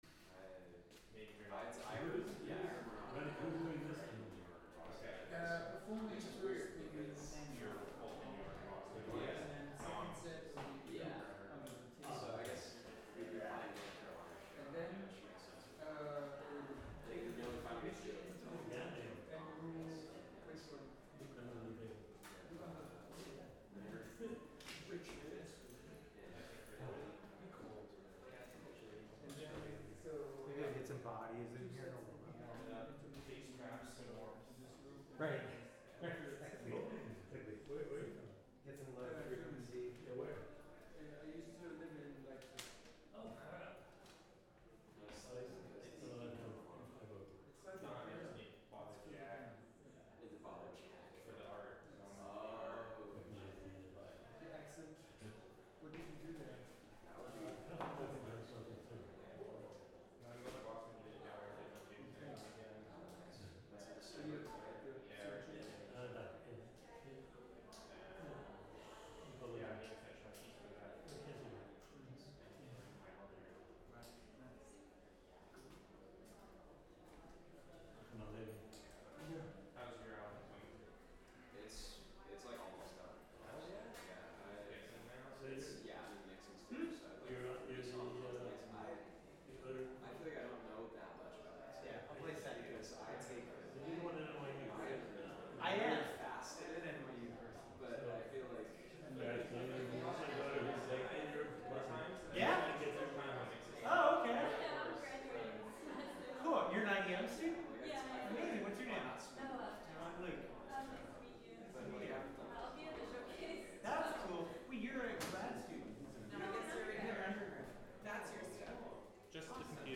live from the Fridman Gallery Listening Room